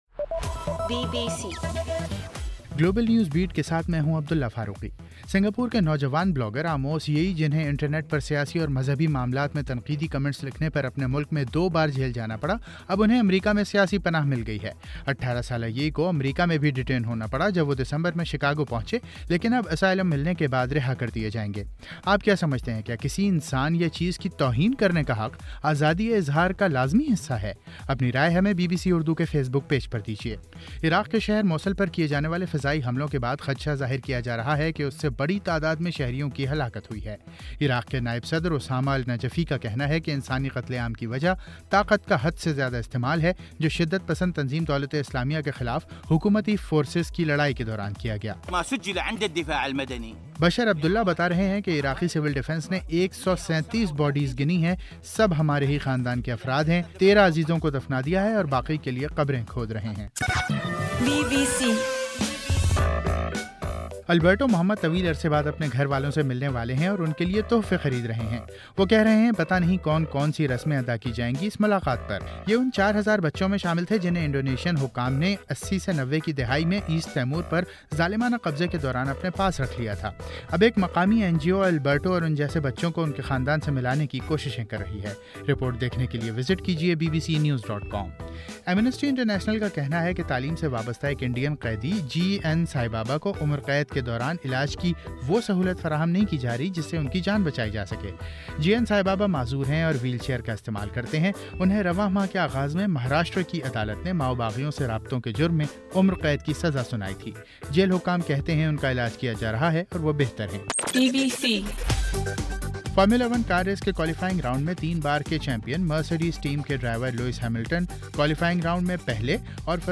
Google+ گلوبل نیوز بیٹ بُلیٹن اُردو زبان میں رات 8 بجے سے صبح 1 بجے تک ہر گھنٹے کے بعد اپنا اور آواز ایف ایم ریڈیو سٹیشن کے علاوہ ٹوئٹر، فیس بُک اور آڈیو بوم پر ضرور سنیے۔